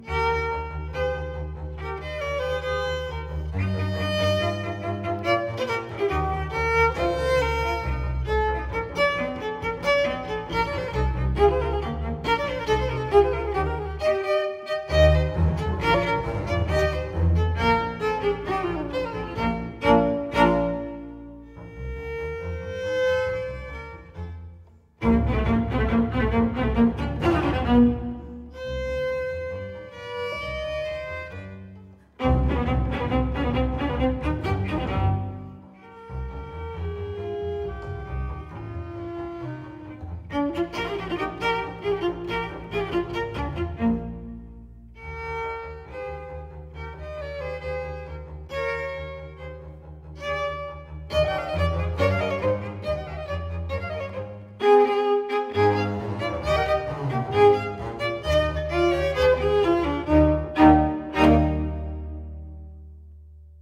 Soundbite 4th Movt
For Violin, Viola & Cello or 2 Violas and Cello